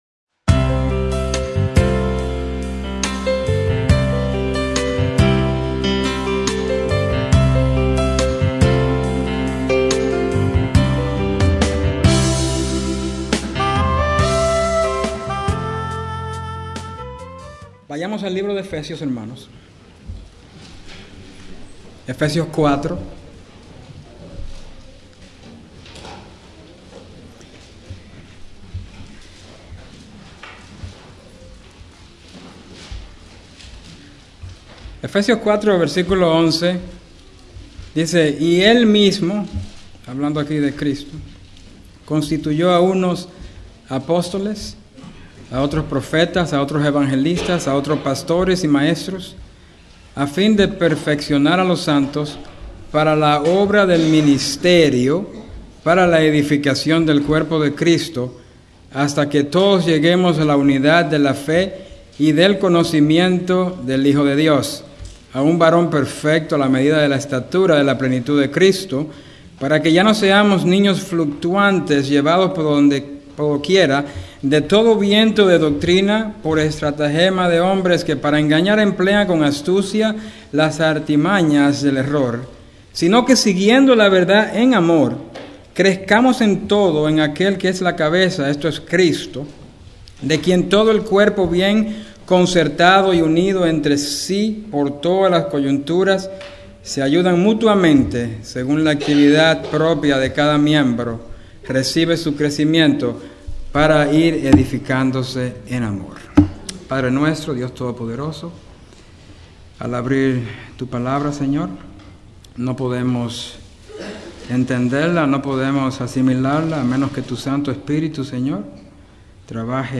Templo Bíblico Providence